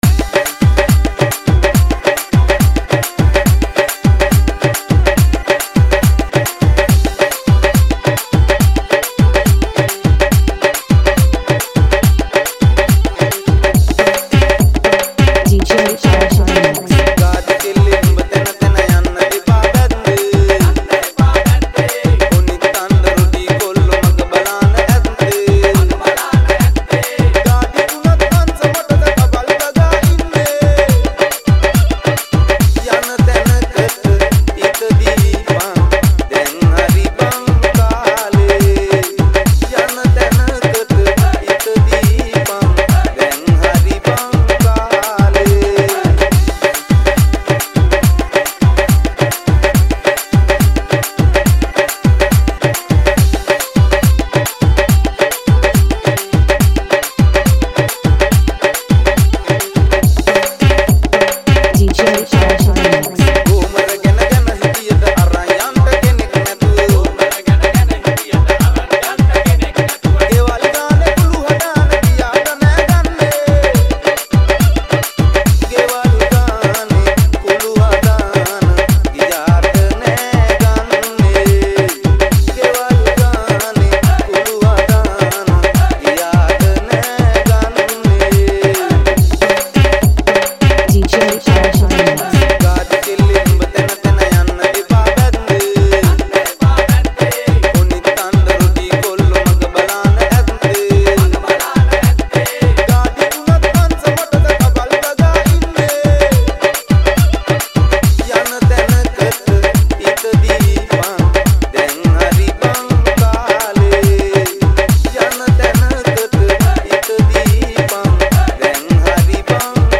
High quality Sri Lankan remix MP3 (3.3).